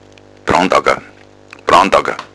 im bayerischen Dialekt